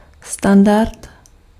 Ääntäminen
Synonyymit norma Ääntäminen Haettu sana löytyi näillä lähdekielillä: tšekki Käännös Ääninäyte Substantiivit 1. standard {m} France (Paris) Esimerkit životní standard . niveau de vie.